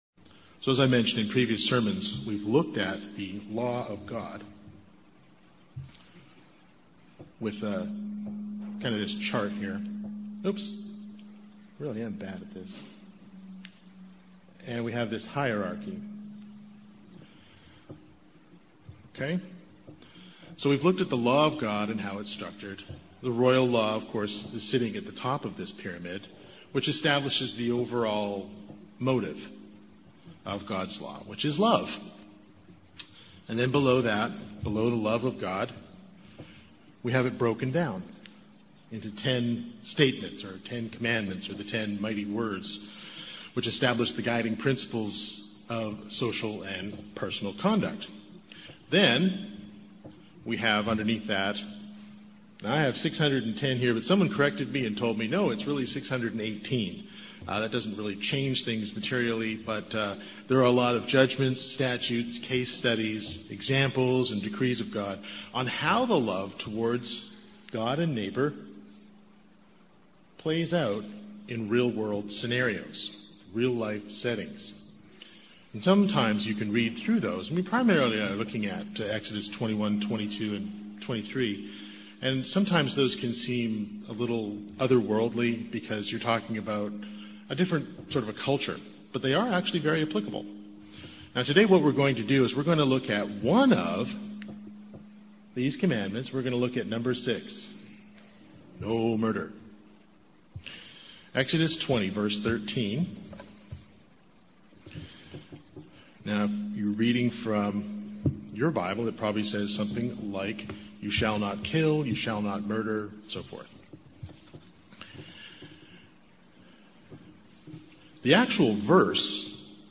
In previous sermons we have looked at the law of God and how it is structured…